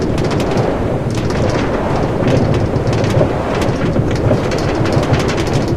minecart_inside.ogg